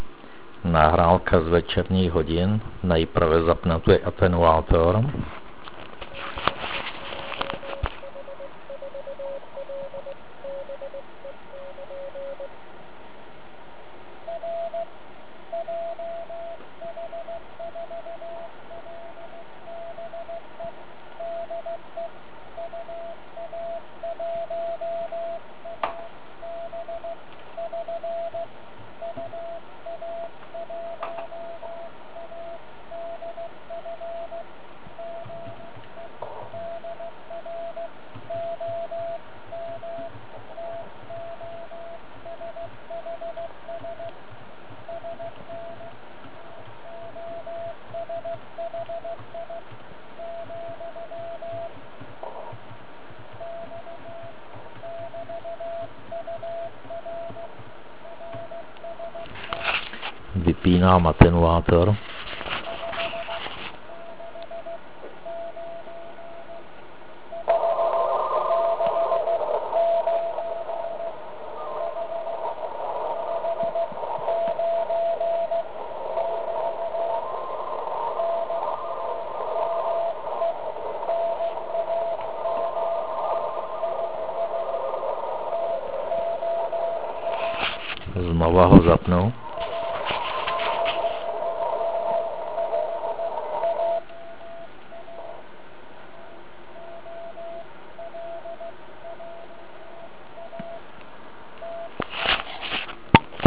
Samozřejmě večer rádio trpí intermodulačními produkty.
Kdyby náhodou někdo nevěděl, jak se to projevuje, pak v následující nahrávce je zapnut ATT a pak vypnut, rozdíl je snad jasný.
Zapnut ATT x Vypnut ATT (wav)